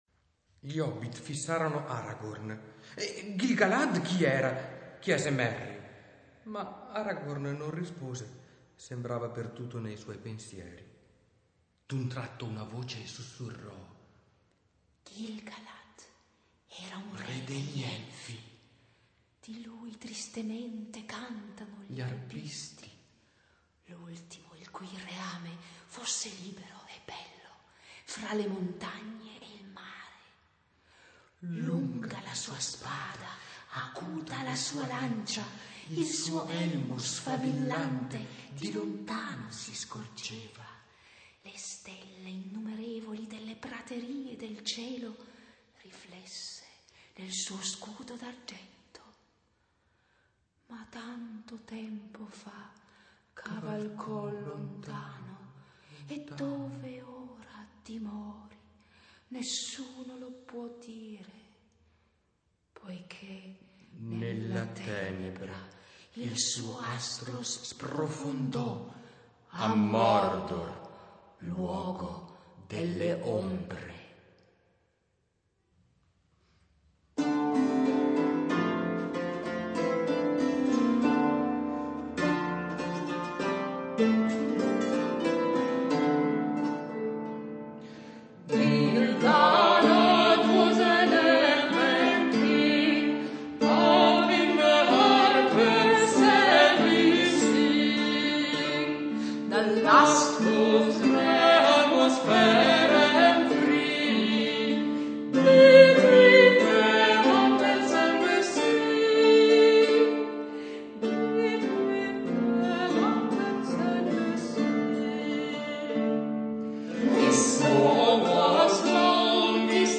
decidemmo di suonare e cantare in duo
Fu un grande piacere presentare questo frutto del nostro far musica, vuoi per la bellezza dei testi, vuoi per la gioia che ci procurò suonare insieme sulle nostre arpe, e cantare su musiche così legate al passato personale.